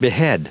Prononciation du mot behead en anglais (fichier audio)
Prononciation du mot : behead